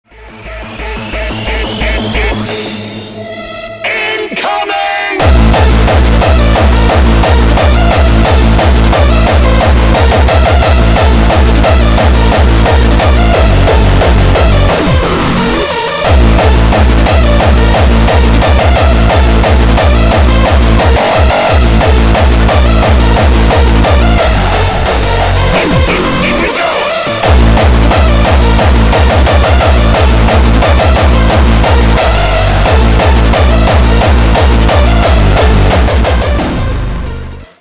Хард Басс